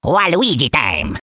One of Waluigi's voice clips in Mario Kart: Double Dash!!